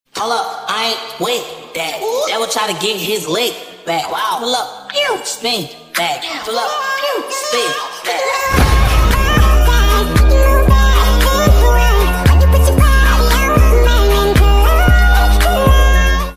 pew pew sound effects free download